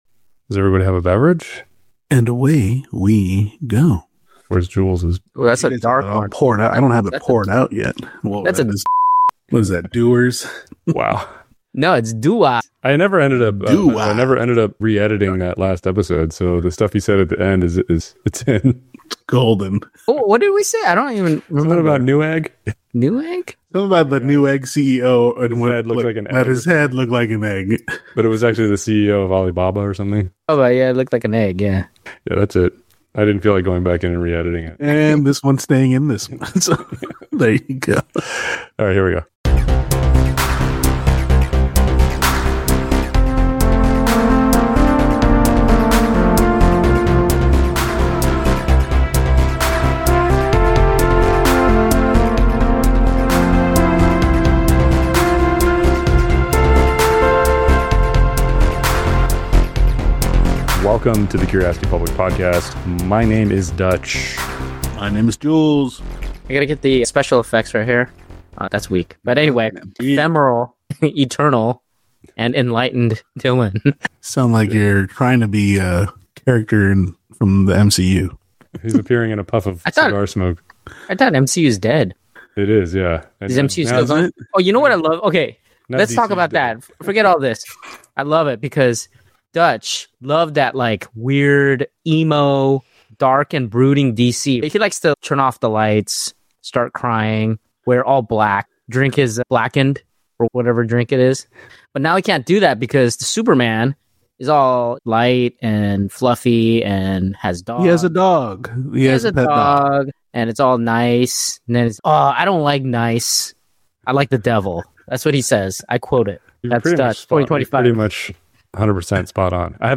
WARNING: this is a bleepfest.